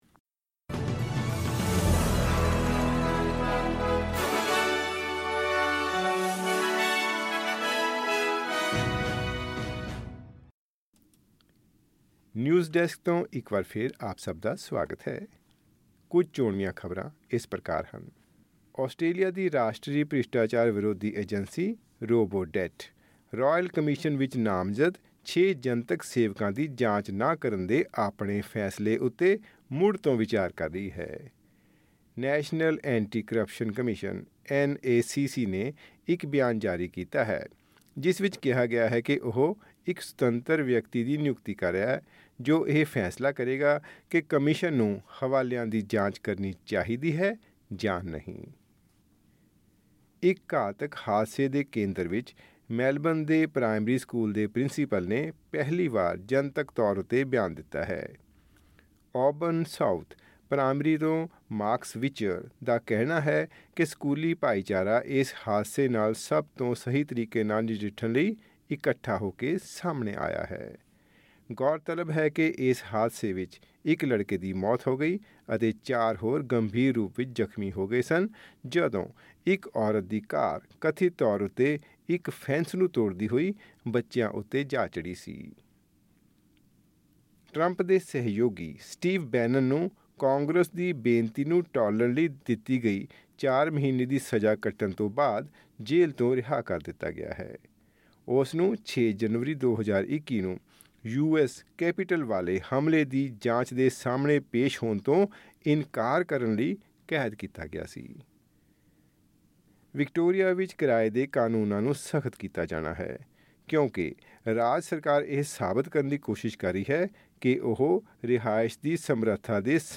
ਐਸ ਬੀ ਐਸ ਪੰਜਾਬੀ ਤੋਂ ਆਸਟ੍ਰੇਲੀਆ ਦੀਆਂ ਮੁੱਖ ਖ਼ਬਰਾਂ: 30 ਅਕਤੂਬਰ 2024